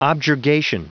Prononciation du mot objurgation en anglais (fichier audio)
Prononciation du mot : objurgation